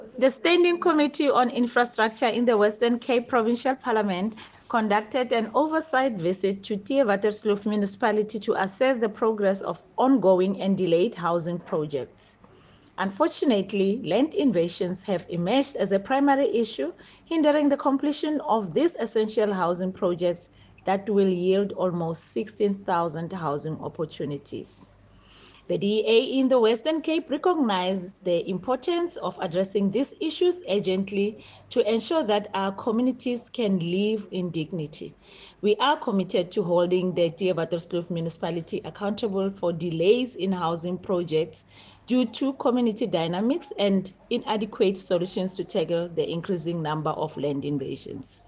attached soundbite by Matlhodi Maseko MPP.